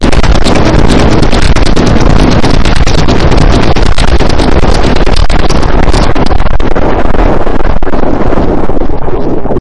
暴风雨
描述：风暴声
Tag: 风暴